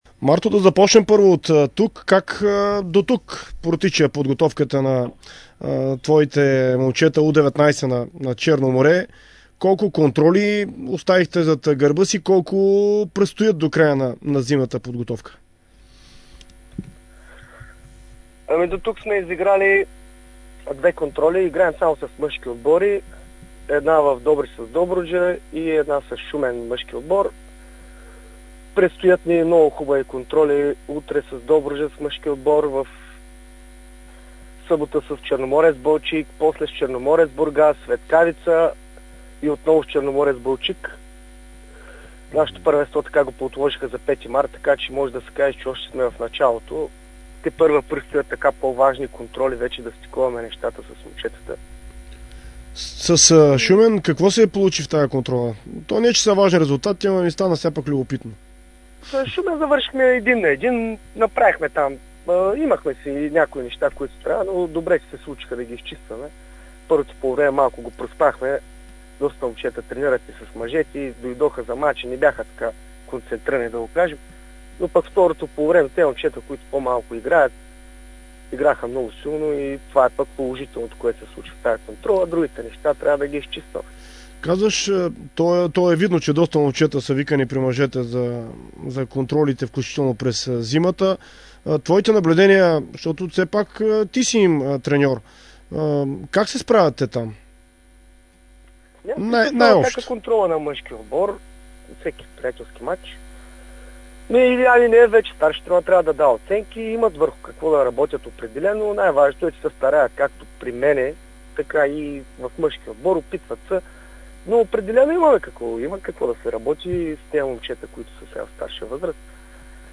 интервю за Дарик радио и dsport